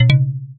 pickup.ogg.mp3